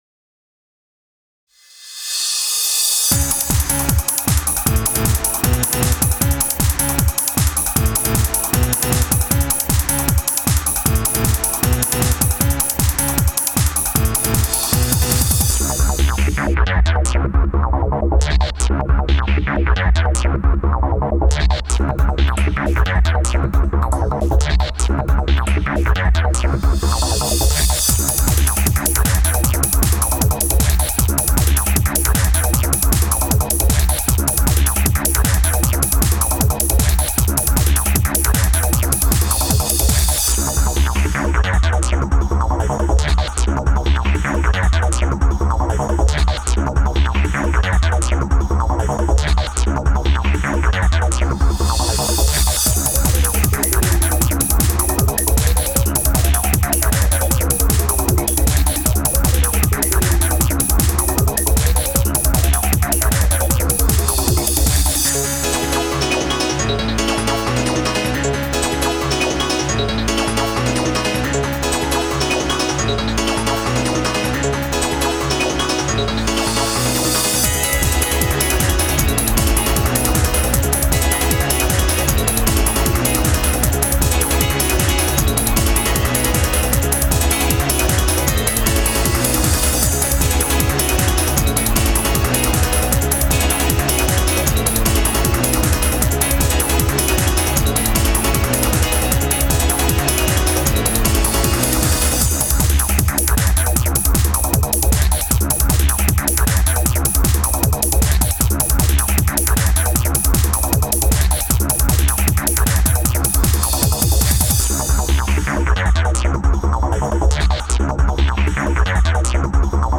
Euphoric Trance
イメージとしては「ねちっこいサウンド」だよ。